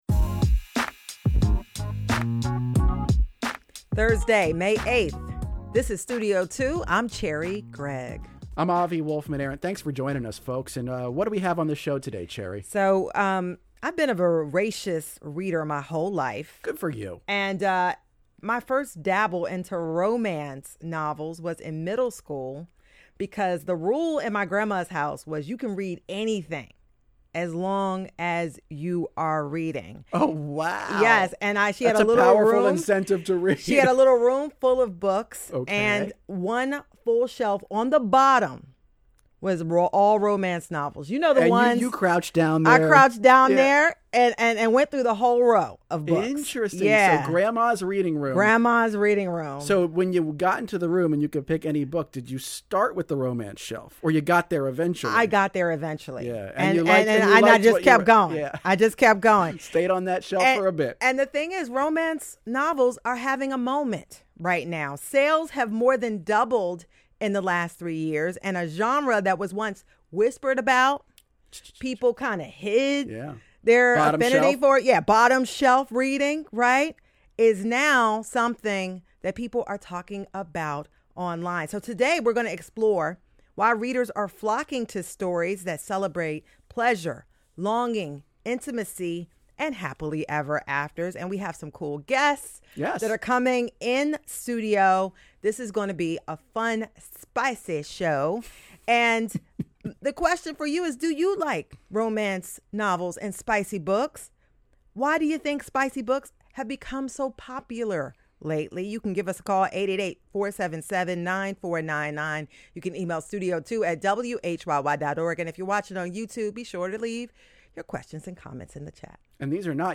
Interview with Dwayne Johnson - WHYY